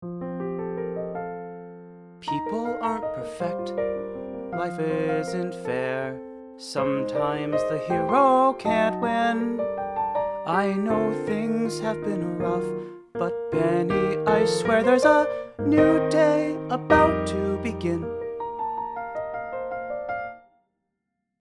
Spoken with some incorrect stresses, marked in red:
Incorrectly set to music: